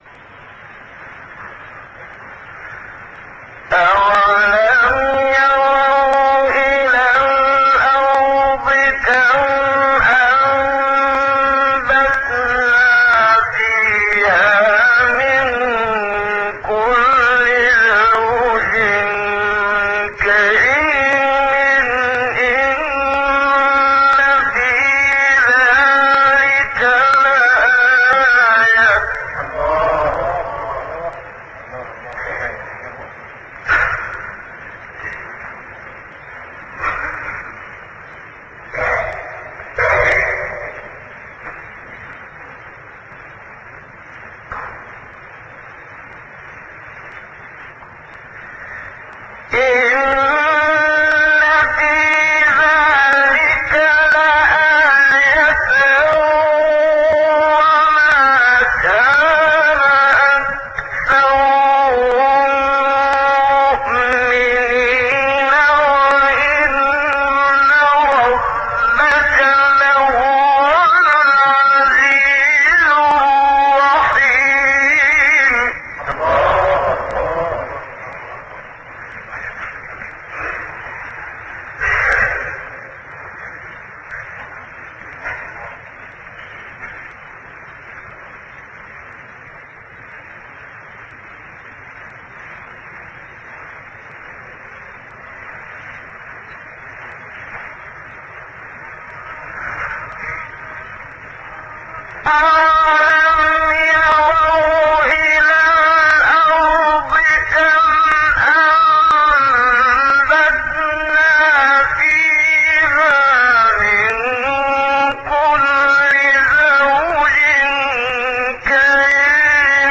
سایت قرآن کلام نورانی - منشاوی بیات (2).mp3